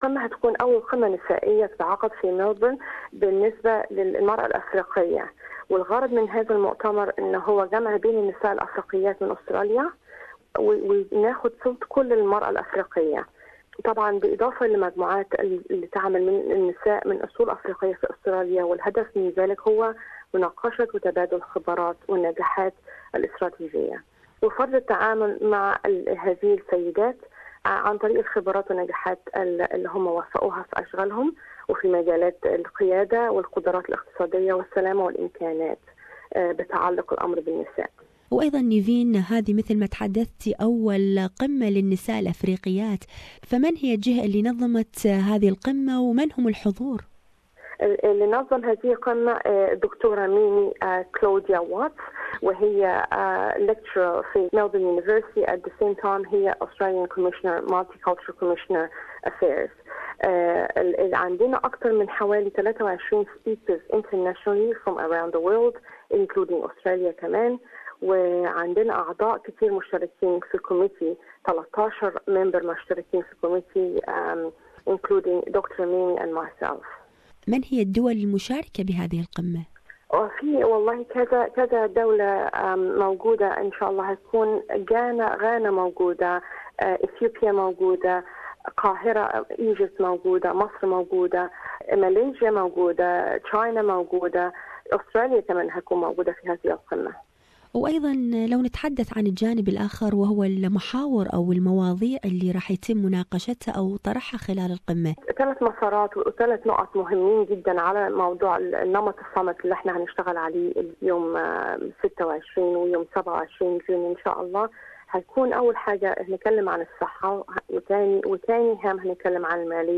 Inaugural African Diaspora Women Summit, 26th and 27th of June 2017, Victoria University Convention Centre, Melbourne Australia. More on this issue, listen to this interview